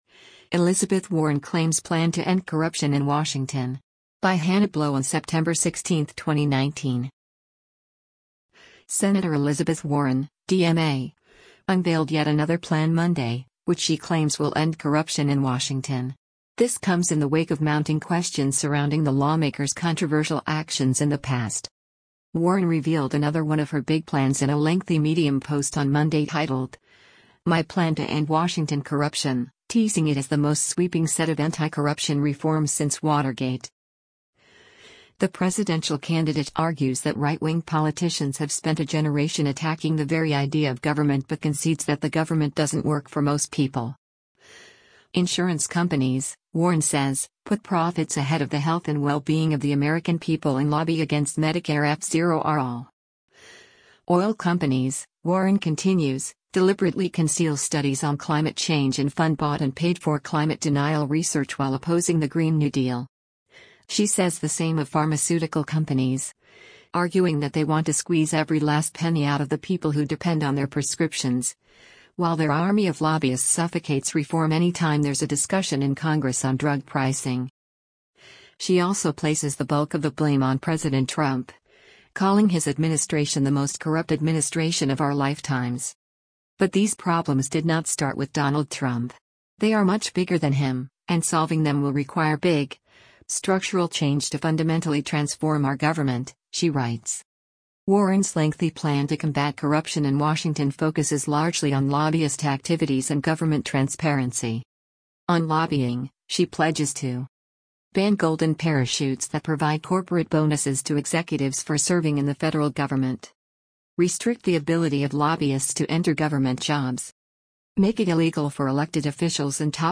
Sen. Elizabeth Warren (D-MA) is interviewed the spin room after the Democratic Presidentia